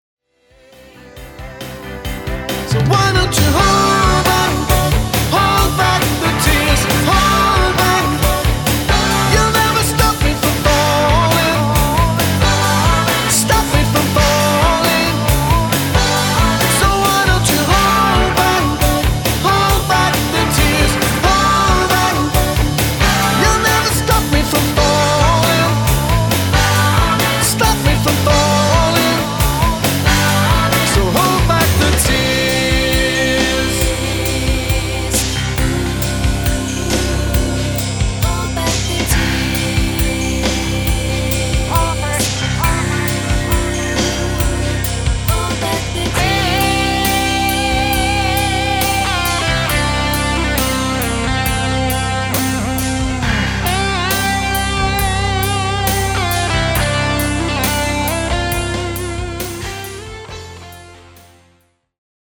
Backing Vocals…
All keyboards and Guitars
Fender ’63 Precision Bass